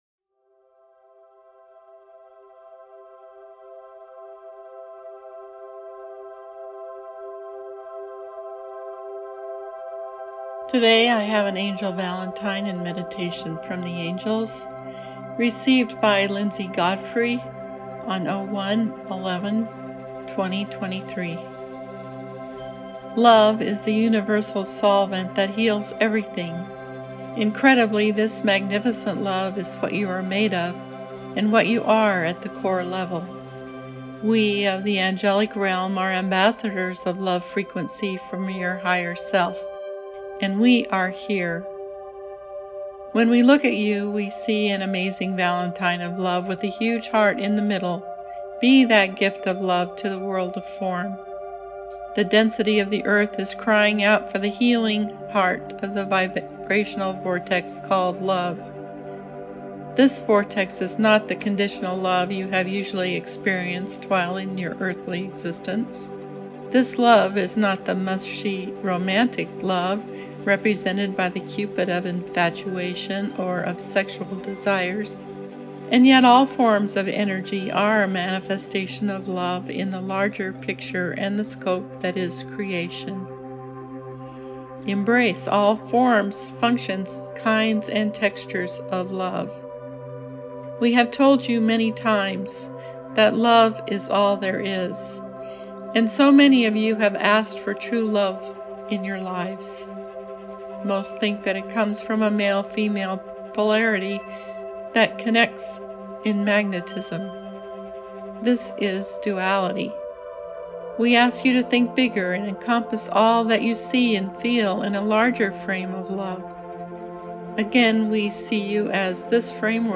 Angel Valentine Meditation